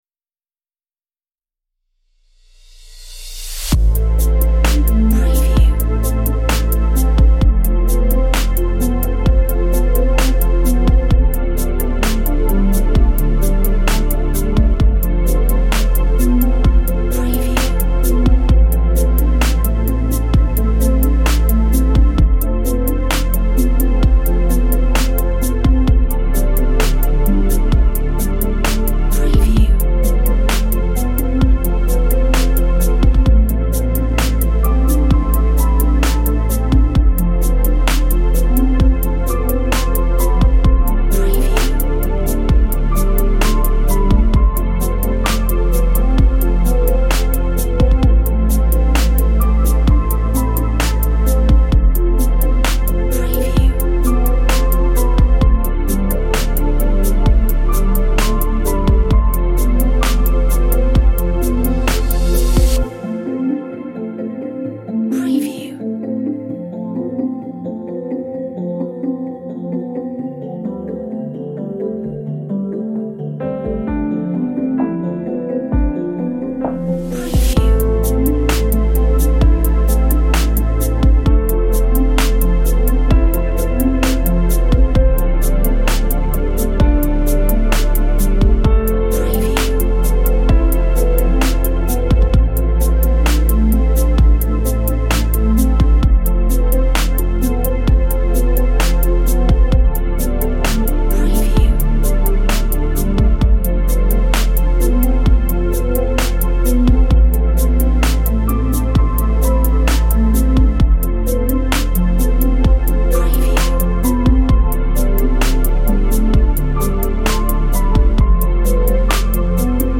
Classy spacious beats